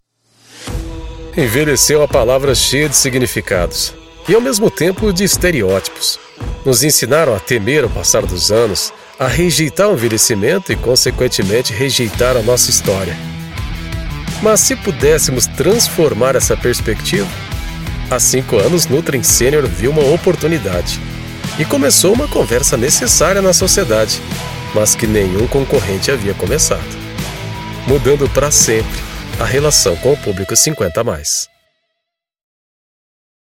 Natural, Amable, Empresarial, Comercial, Versátil
Explicador
He presents his voice with great agility.